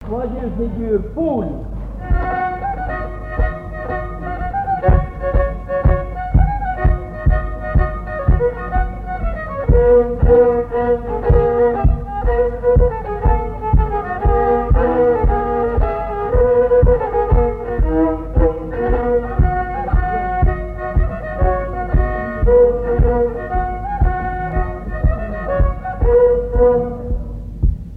Mémoires et Patrimoines vivants - RaddO est une base de données d'archives iconographiques et sonores.
danse : quadrille : poule
Assises du Folklore
Pièce musicale inédite